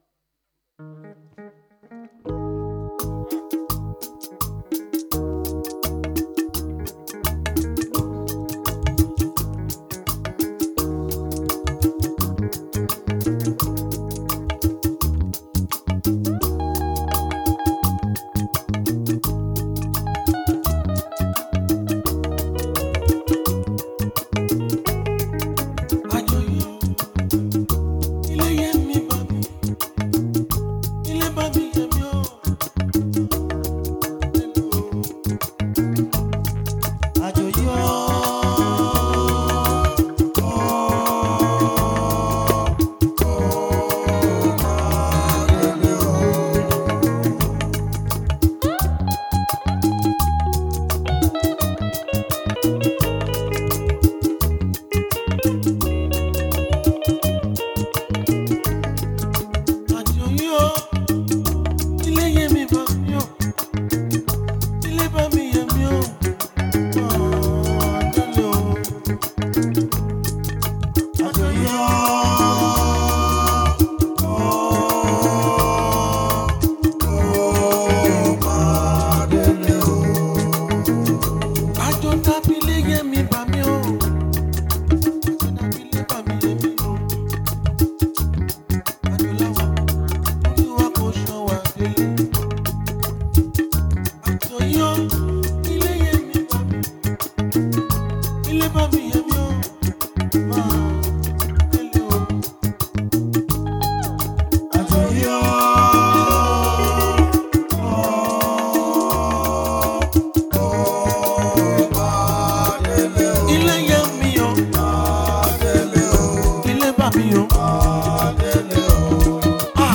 done as A Special tribute to him by a Juju Musician